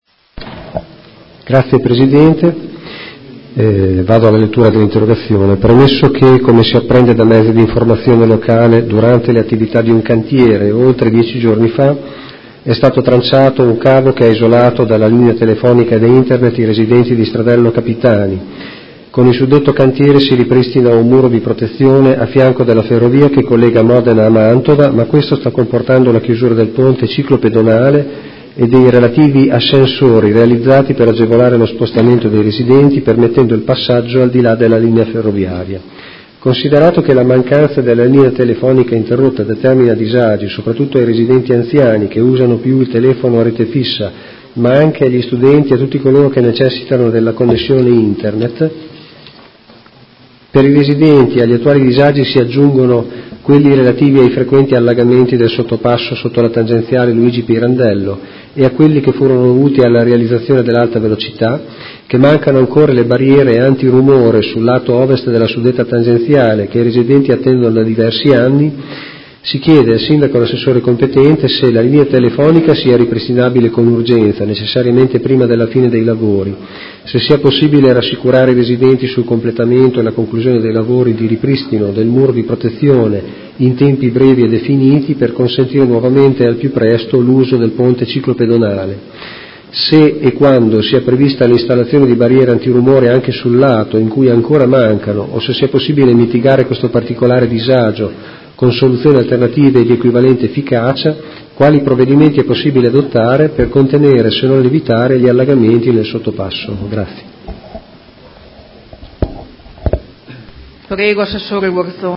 Seduta del 10/01/2019 Interrogazione dei Consiglieri Malferrari e Stella (Art1-MDP/Per Me Modena) avente per oggetto: Vecchi e nuovi disagi per i residenti di Stradello Capitani